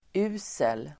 Uttal: ['u:sel]